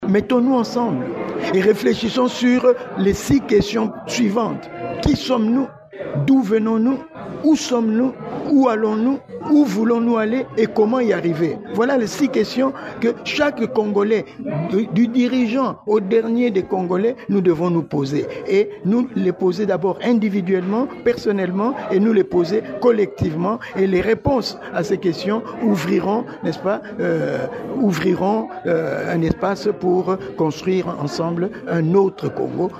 a insisté sur la mise en œuvre de cette valeur, ce lundi 16 février, lors de la commémoration de la journée du 34 e anniversaire des martyrs de la démocratie, à Kinshasa.